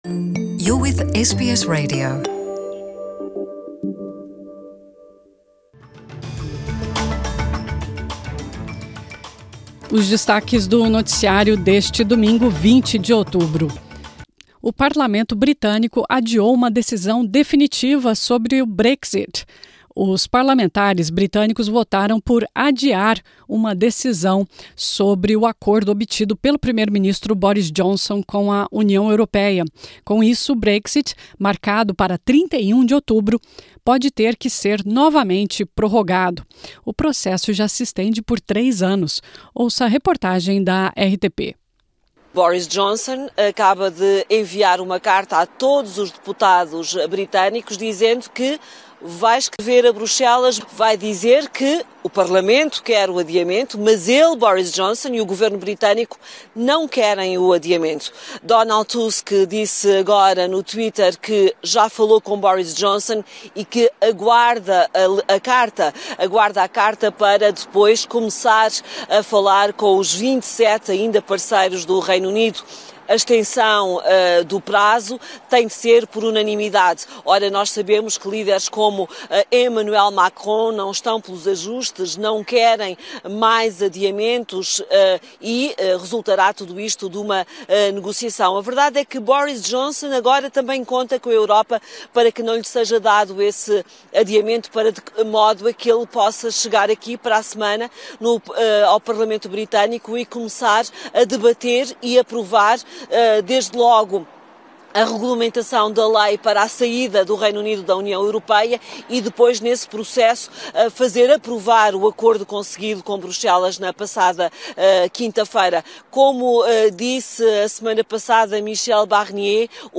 Listen to today’s news headlines, in Portuguese.